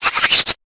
Cri de Sovkipou dans Pokémon Épée et Bouclier.